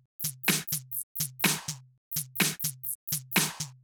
Drumloop 125bpm 10-B.wav